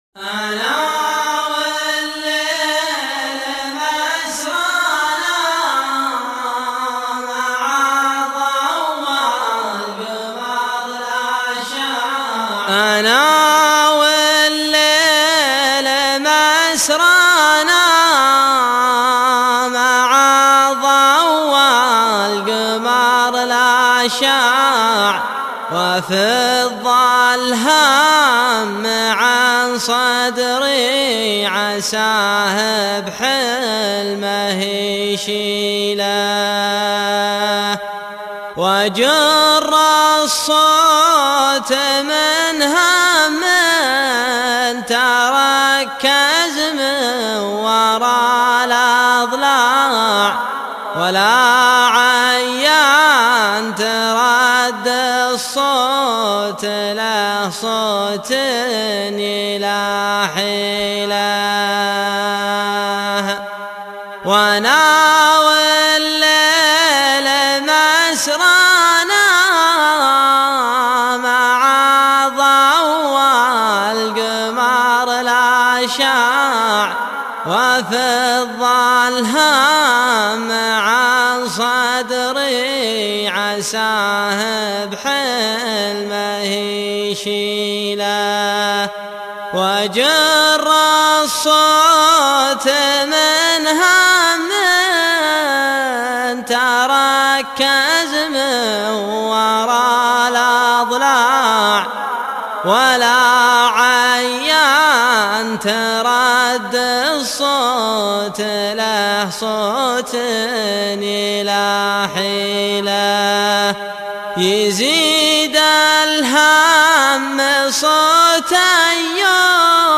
شيله